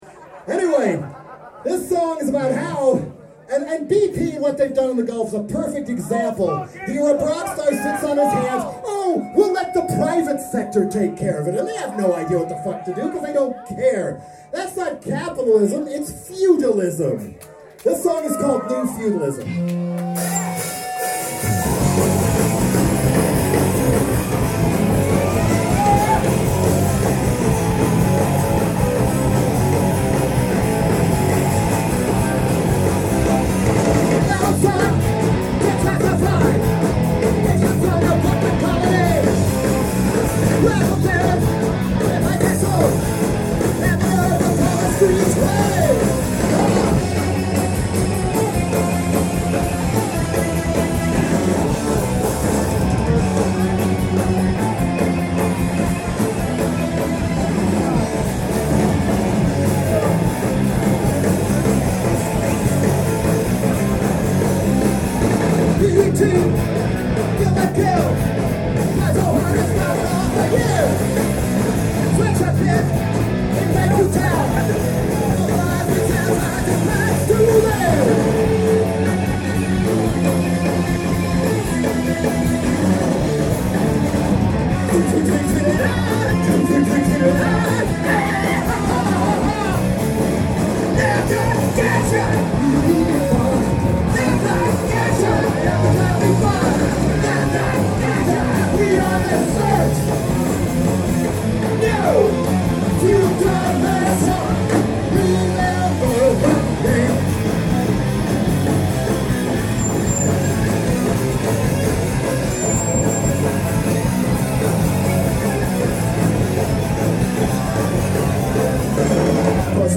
Rocks Off Cruise – May 28, 2010